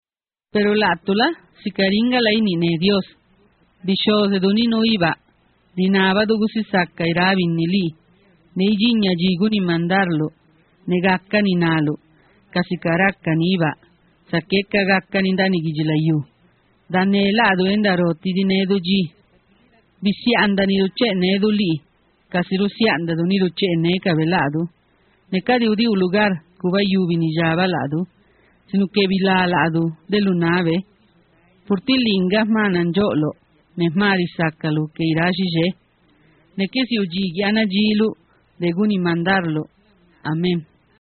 mexico_zapotec_prayer.mp3